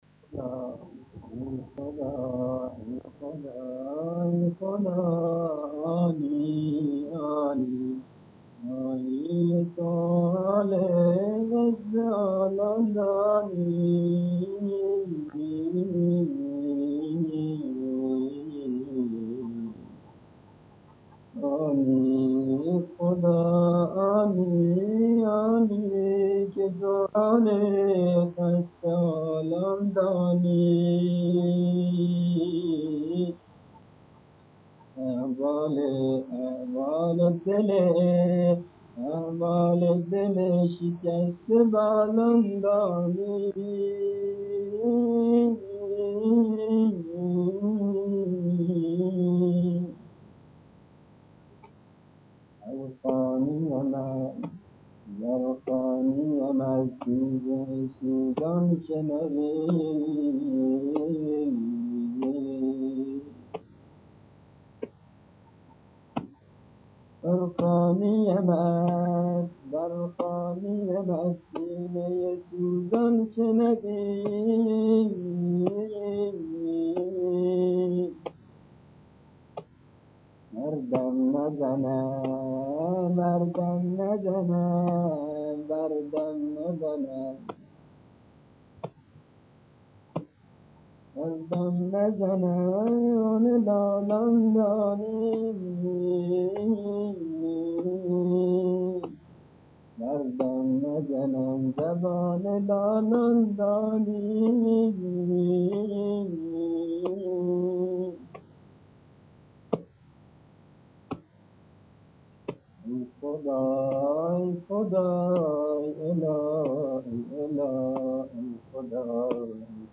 صوت شهدا: نوای مداحی